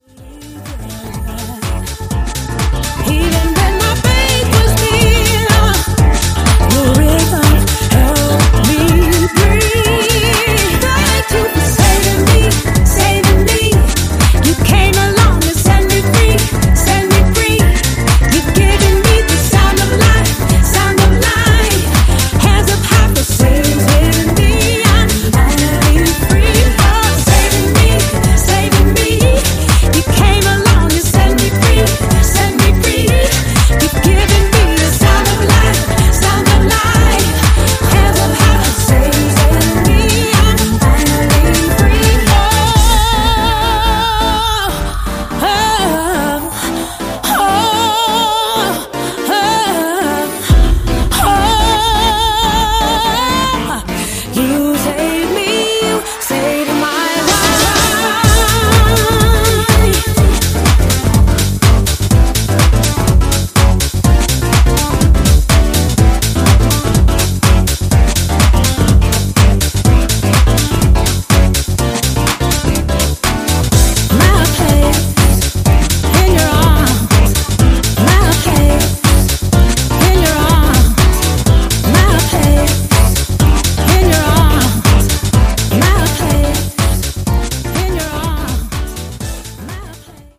Vocal Mix